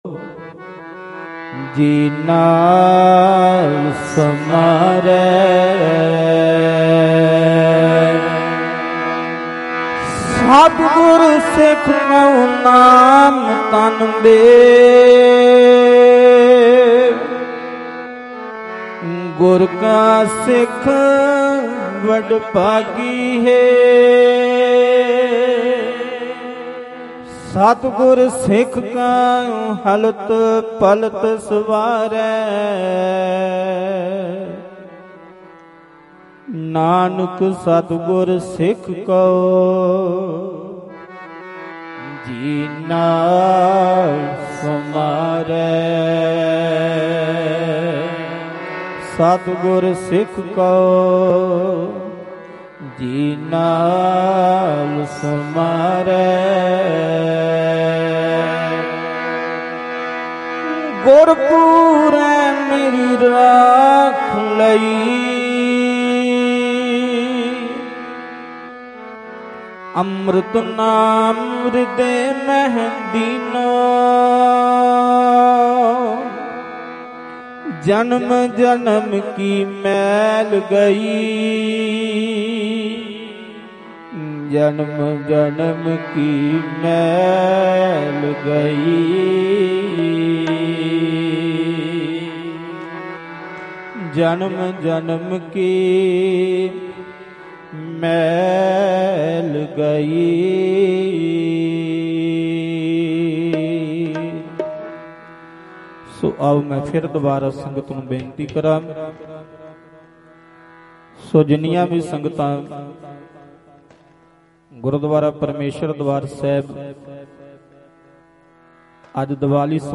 25 Dec 2021 Dhadrianwale Diwan at Gurdwa | Dhadrianwale
Mp3 Diwan Audio by Bhai Ranjit Singh Ji Khalsa Dhadrianwale